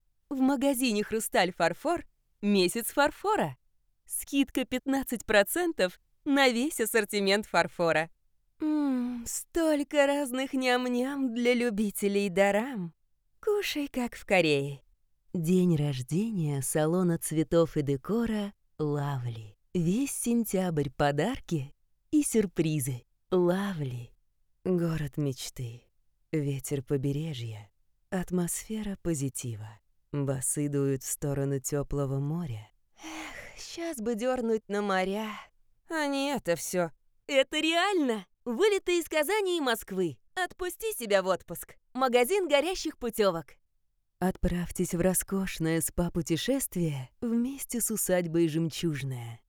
Тракт: микрофон SE Electronics x1,звуковая карта Focusrite Scarlett Solo 2nd Gen
Демо-запись №2 Скачать